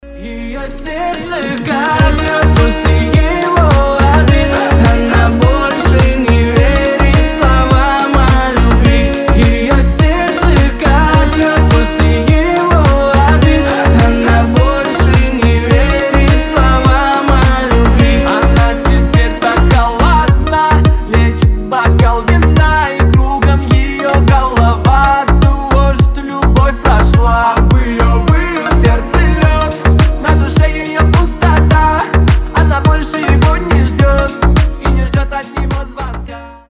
• Качество: 320, Stereo
мелодичные